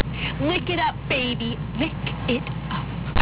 If you would like to hear what my feline friend likes to do with my homebrew, just click on her image.
lickitup.au